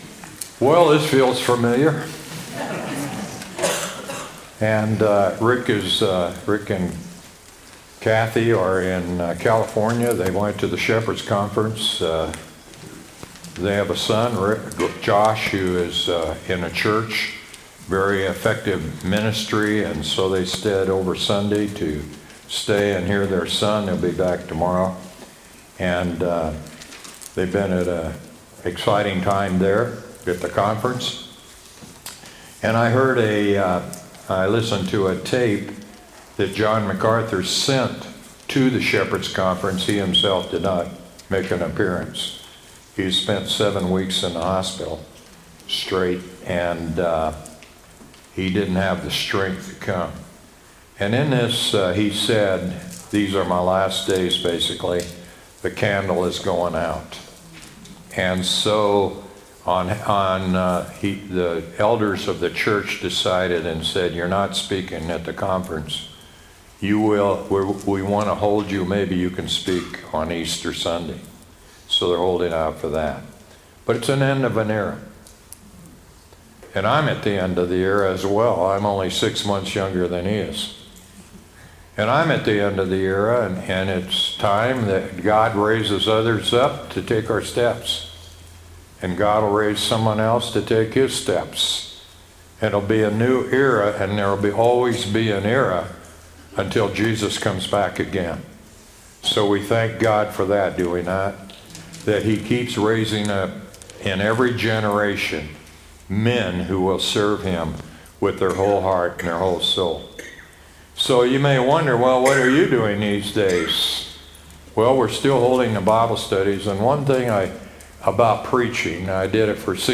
sermon-3-9-25.mp3